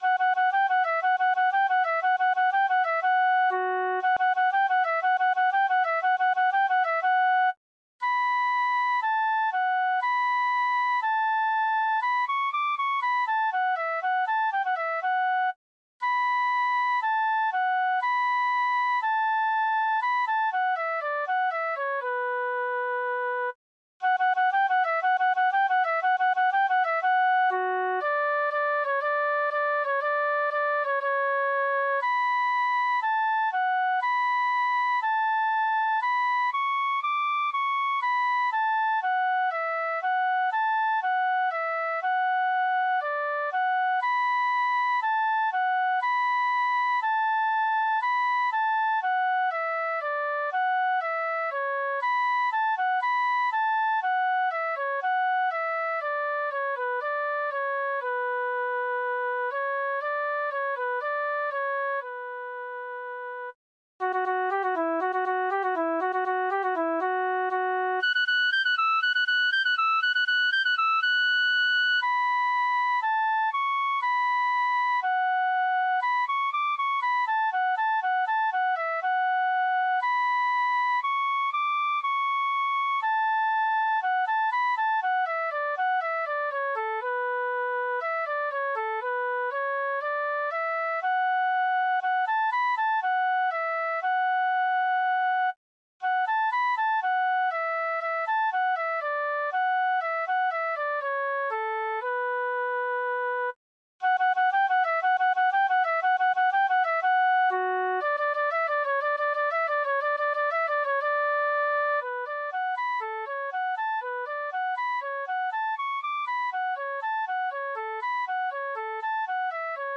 for Solo Flute